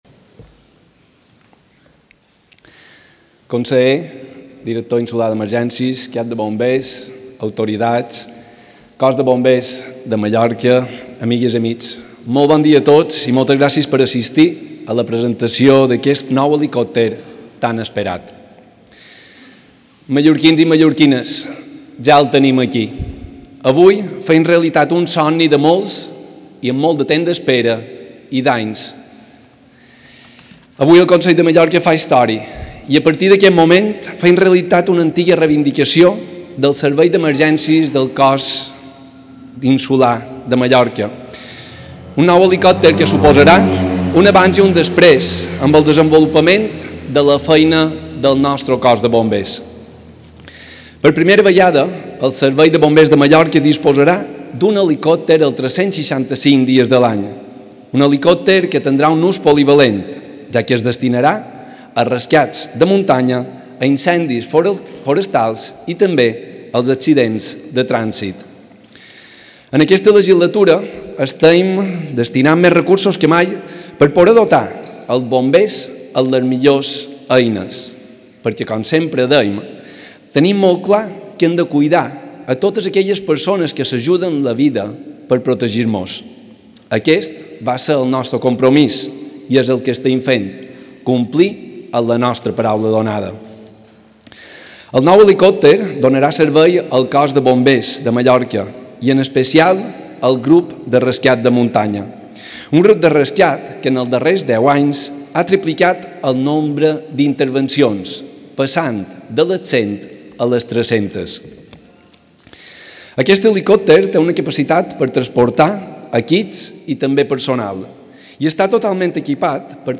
Podéis acceder a las declaraciones del presidente del Consell de Mallorca, Llorenç Galmés, si clicáis
declaracions-del-president-del-consell-de-mallorca-llorenc-galmes-mpeg